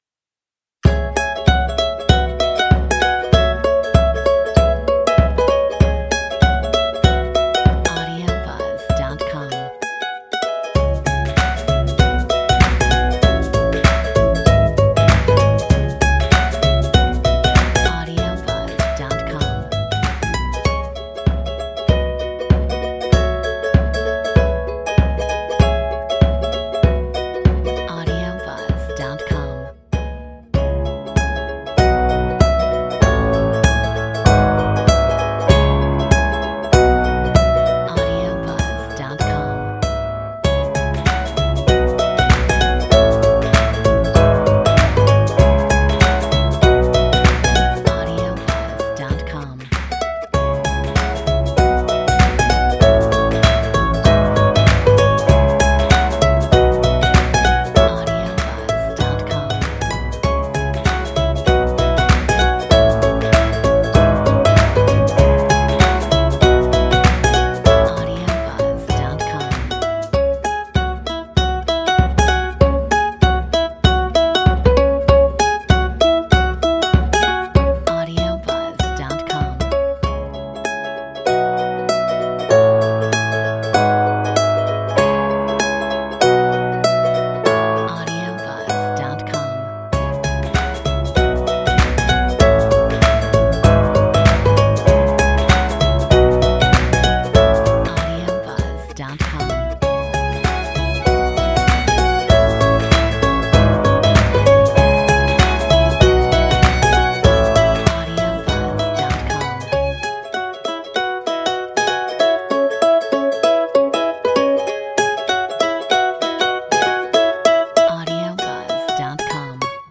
Metronome 97